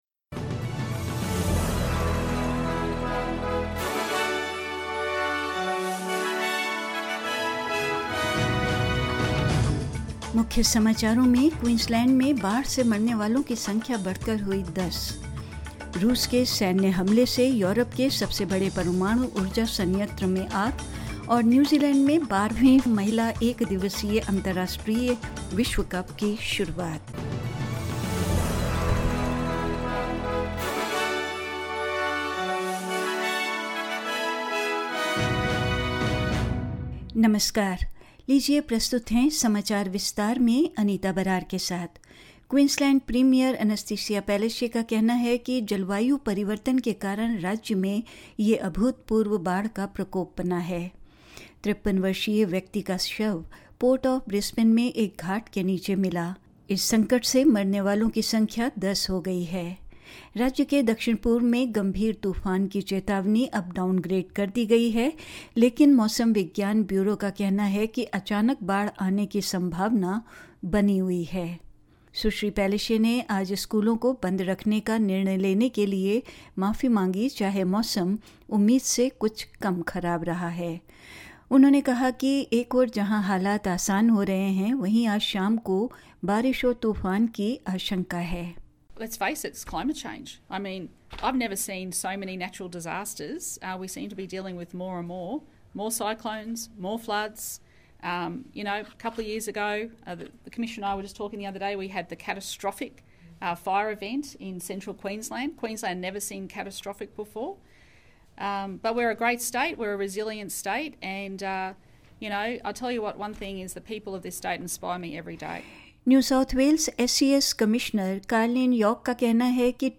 In this latest SBS Hindi bulletin: The death toll from the Queensland floods rises to 10; A fire breaks out at Europe's largest nuclear power plant following a Russian military attack; The 12th Women's One Day International ((ODI)) World Cup gets underway in New Zealand and more news.